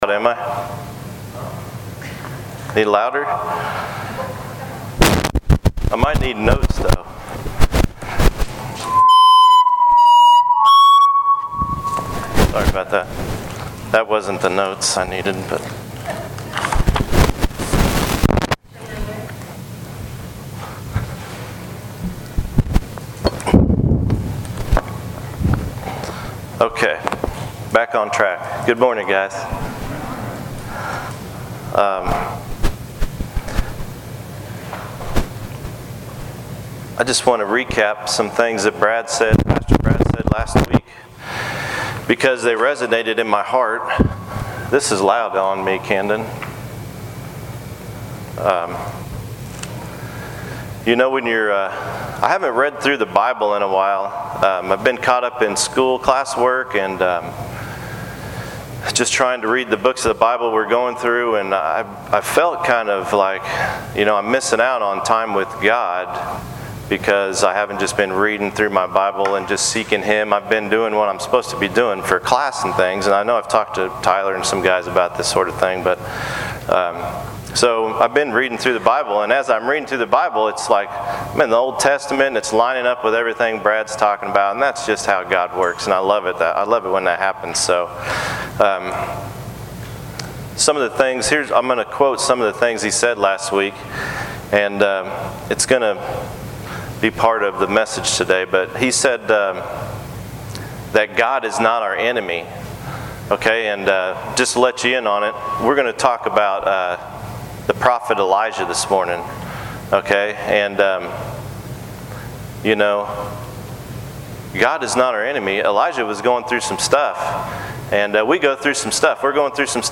Guest sermon by New Life Baptist Church member